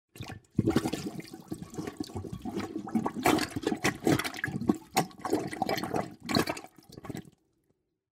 Звук засоренного унитаза